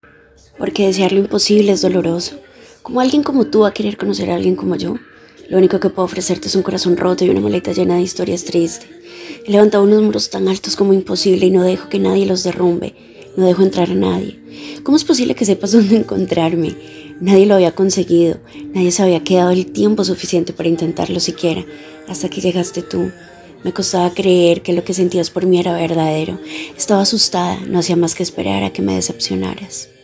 kolumbianisch
Sprechprobe: eLearning (Muttersprache):